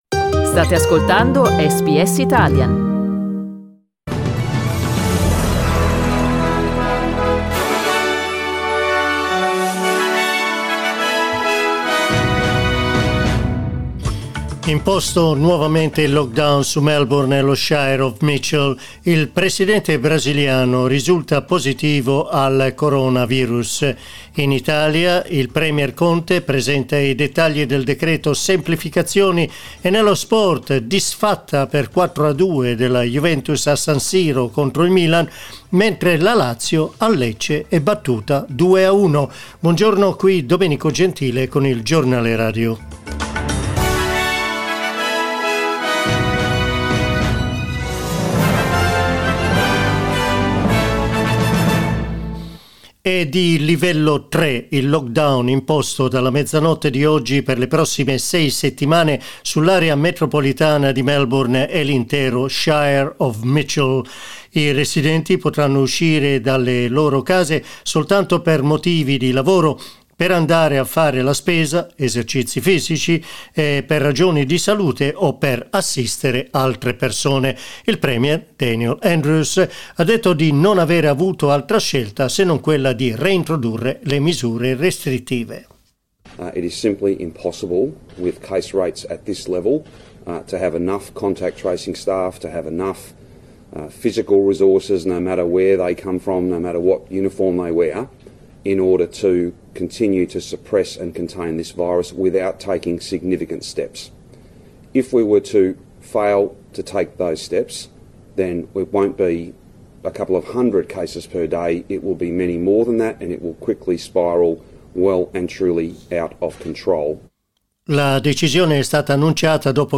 Our news bulletin (in Italian)